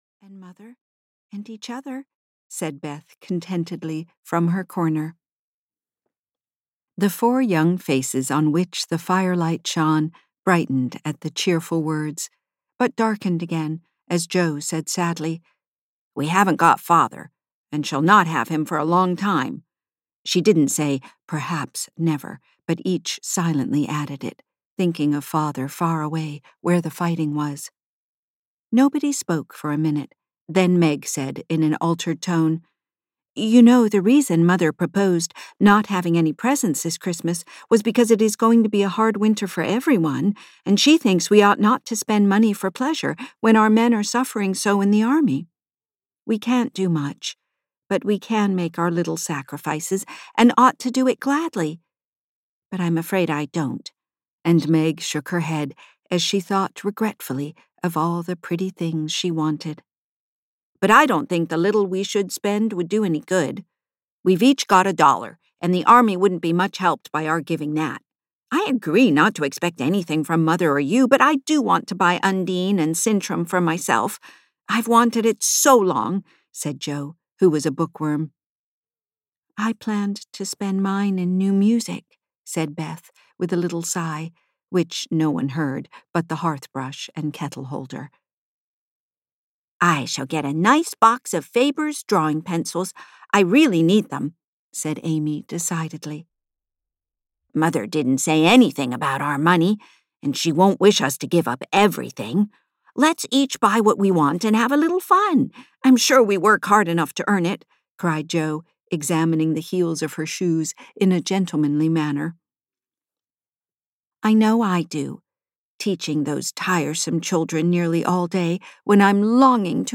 Little Women (EN) audiokniha
Ukázka z knihy